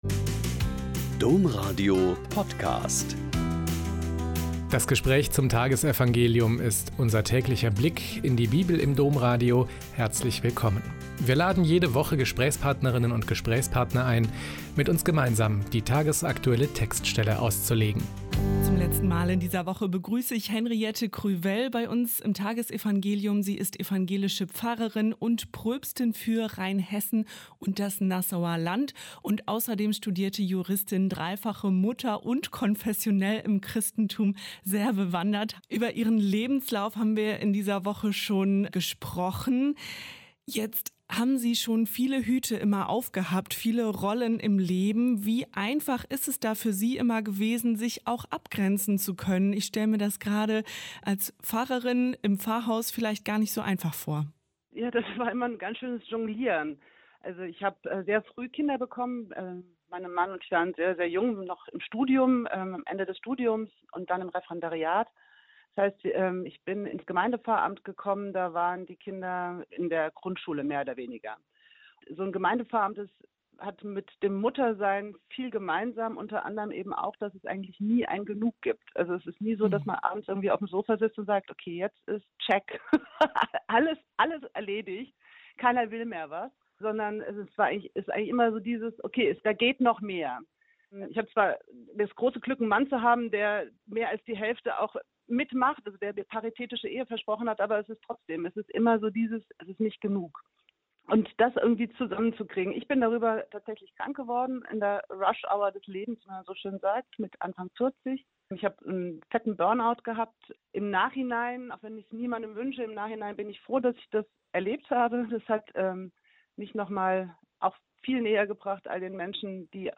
Mt 19,13-15 - Gespräch